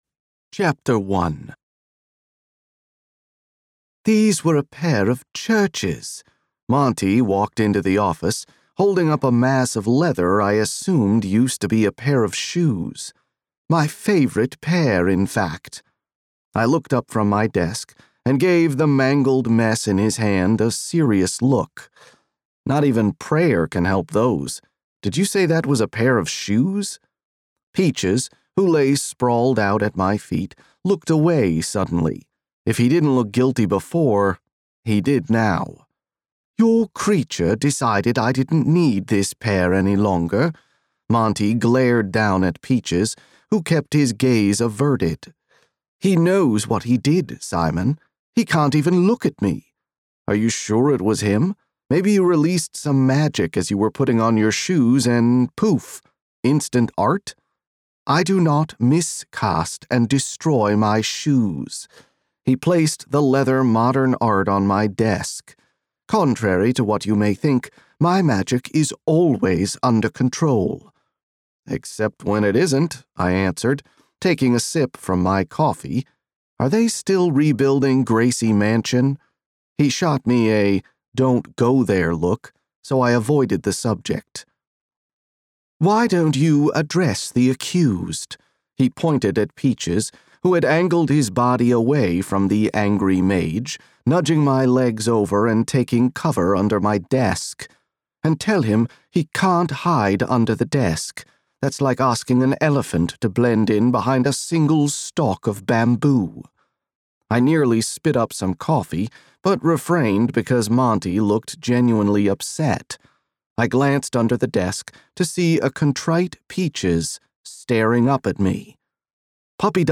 • Audiobook
002_A-Proper-Hellhound_Chpt_1.mp3